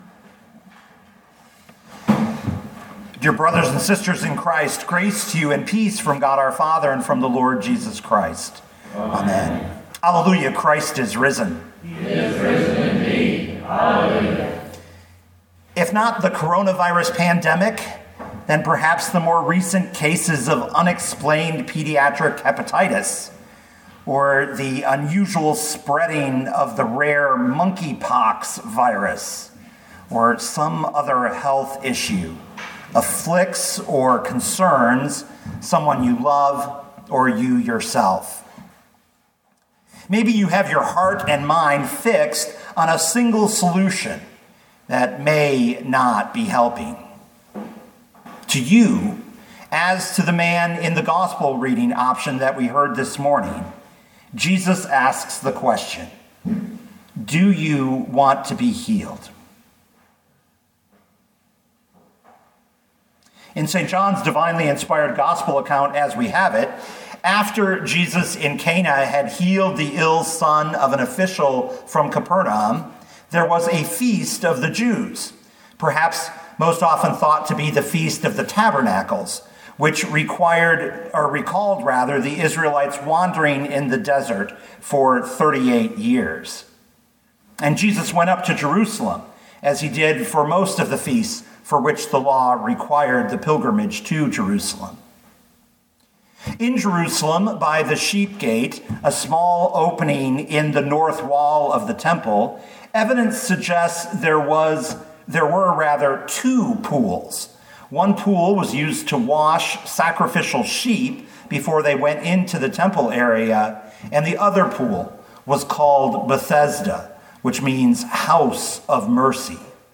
2022 John 5:1-9 Listen to the sermon with the player below